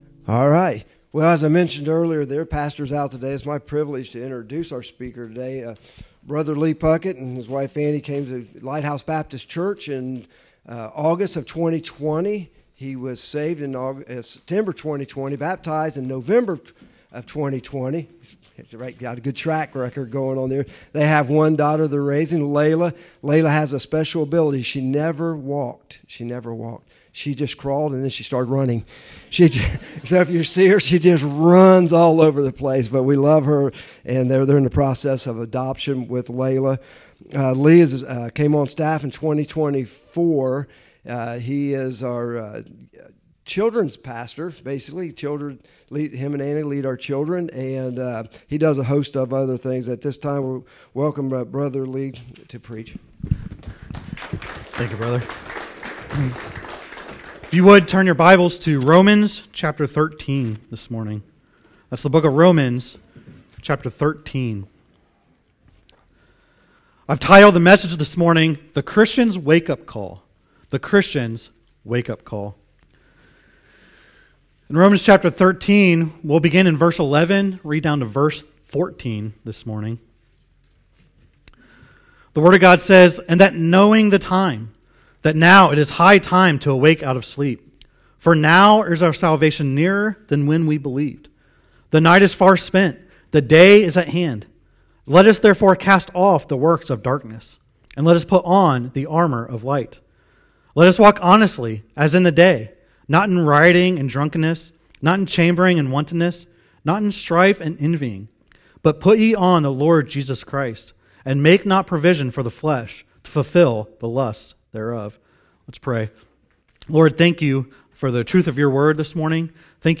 The Christian’s Wake-Up Call – Lighthouse Baptist Church, Circleville Ohio